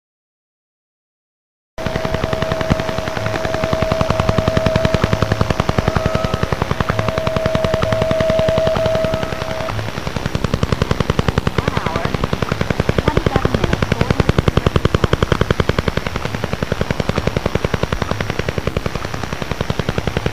Duga-1 earned the name the Russian Woodpecker from amateur radio enthusiasts because of the repetitive tapping noises. The tapping was caused by the radar’s frequency hopping between different frequencies in order to avoid interference and jamming.
Sound-clip-of-sound-transmitted-by-DUGA-3.mp3